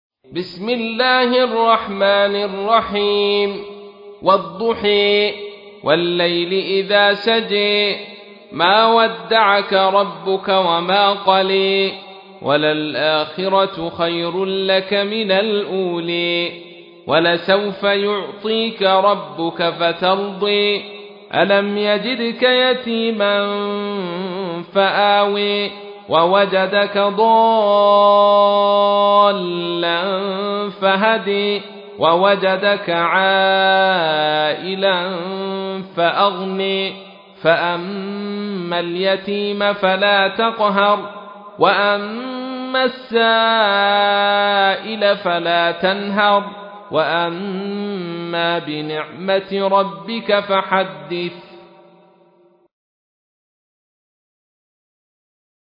تحميل : 93. سورة الضحى / القارئ عبد الرشيد صوفي / القرآن الكريم / موقع يا حسين